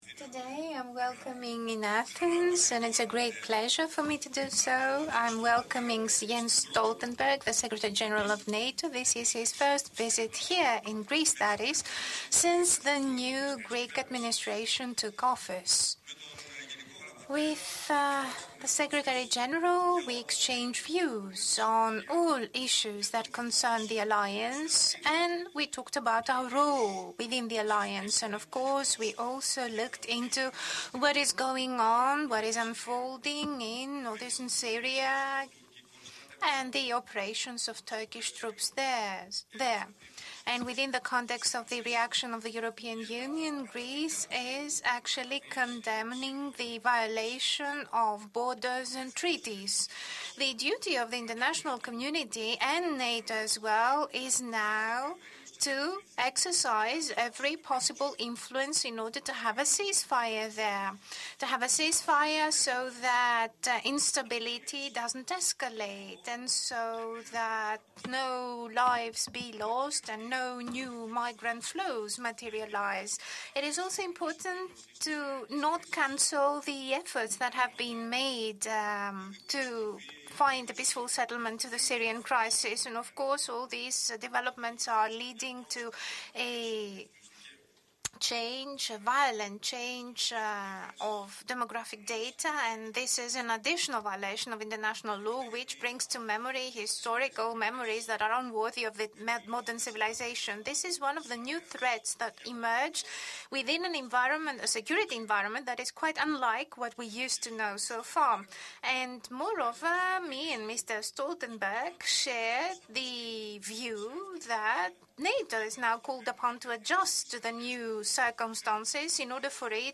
Joint press conference
by NATO Secretary General Jens Stoltenberg and the Prime Minister of the Hellenic Republic, Kyriakos Mitsotakis